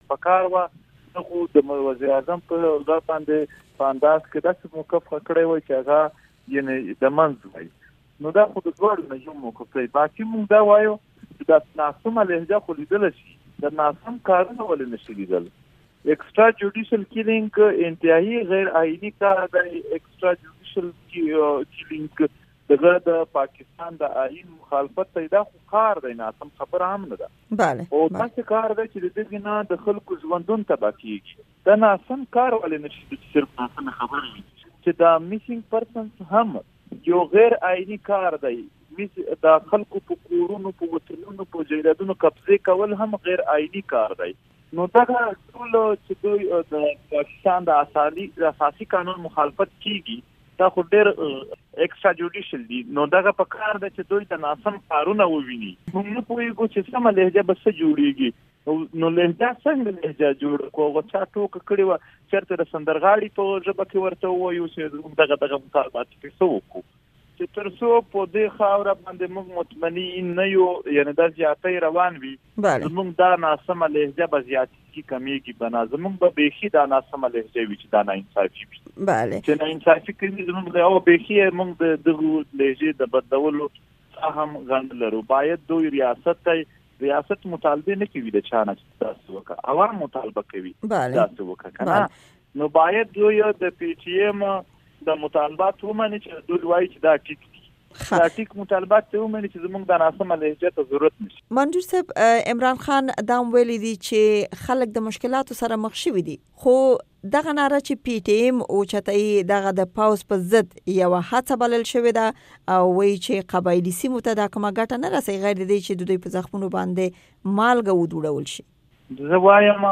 منظور پښتين سره د امريکا غږ بشپړه مرکه دلته اوريدلې شئ:
د منطور پښتین مرکه